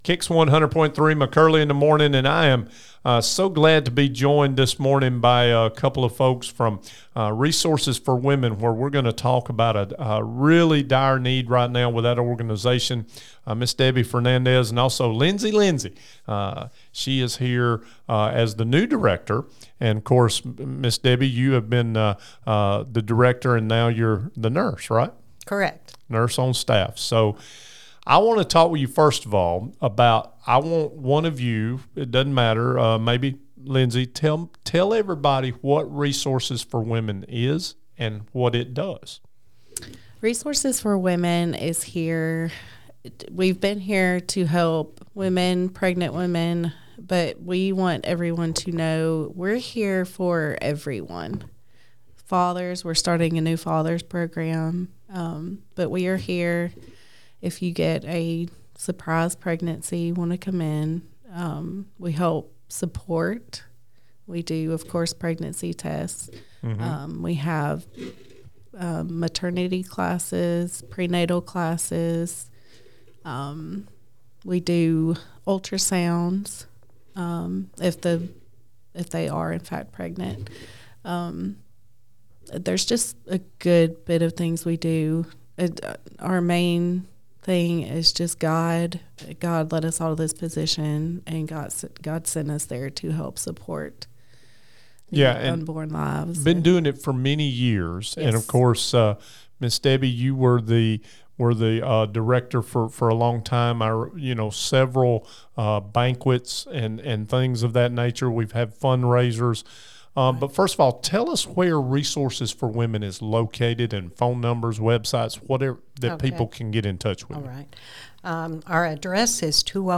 Live with Resources For Women